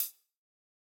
ORG Closed Hat.wav